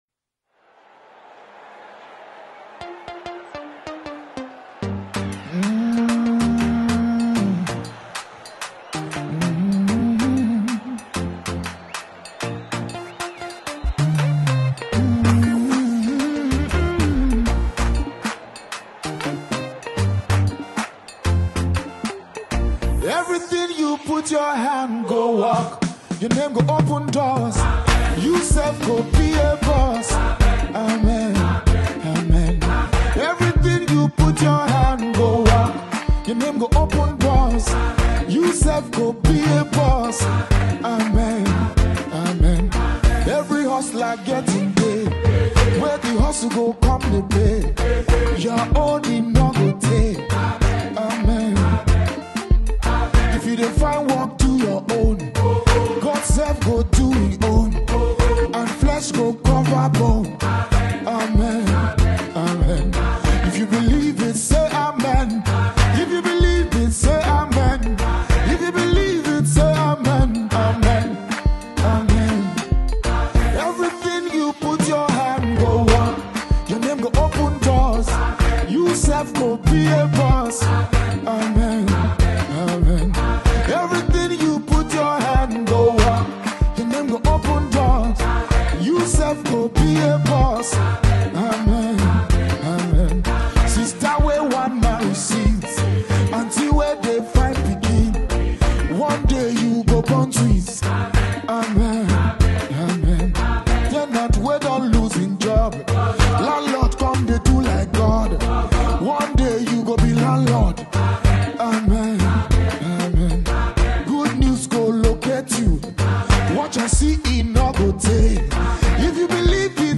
Single Soloist singer R&B Nigerian artist